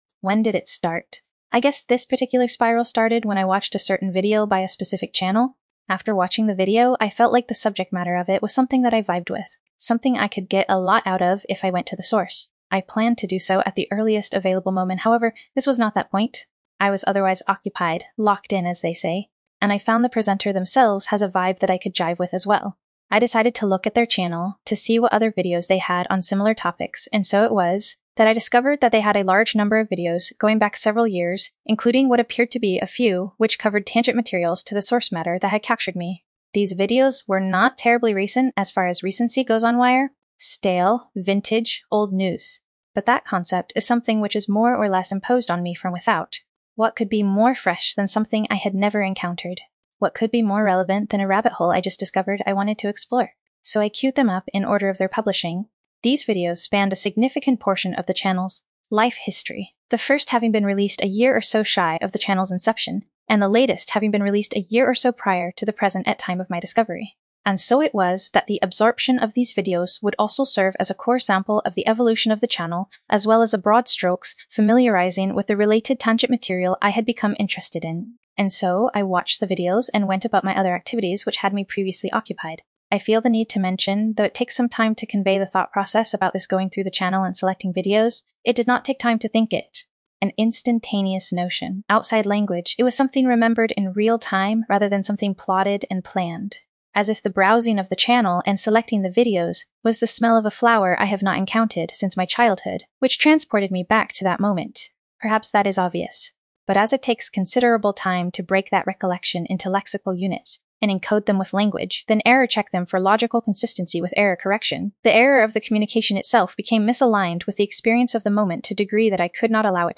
atemporal_dreamscape denpa focus law safe